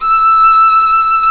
Index of /90_sSampleCDs/Roland L-CD702/VOL-1/STR_Viola Solo/STR_Vla1 % + dyn